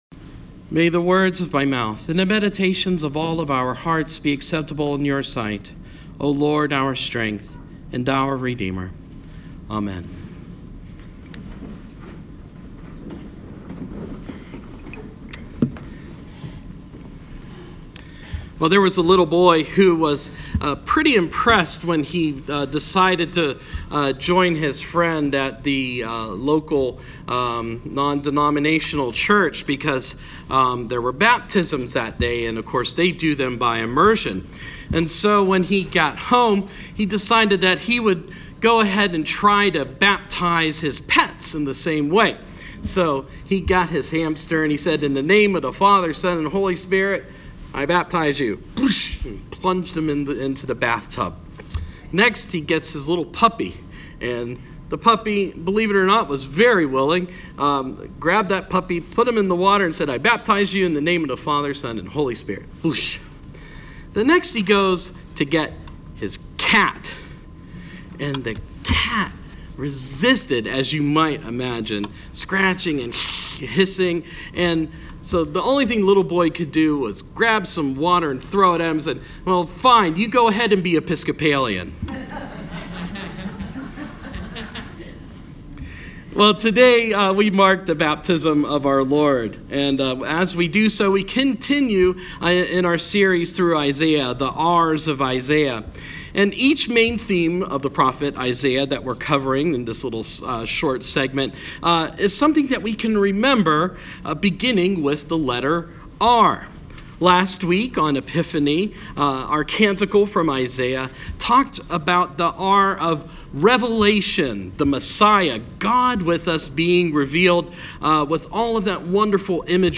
Sermon – Baptism of Our Lord